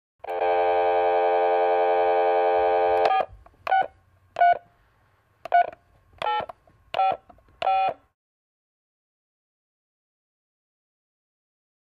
Futzed Dial Tone, And Push Button Dialing Generic Phone Number.